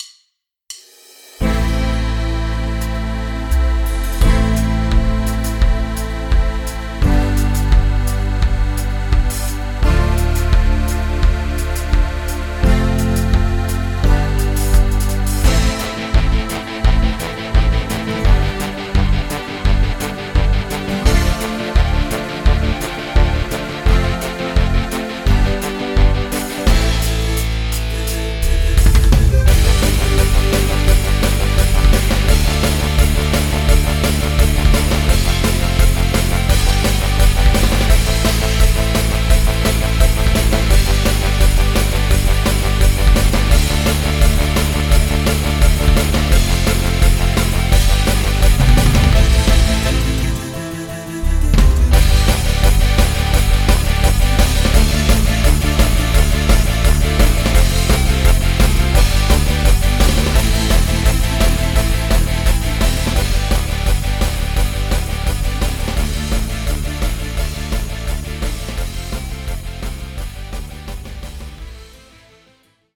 Playback, Instrumental, Karaoke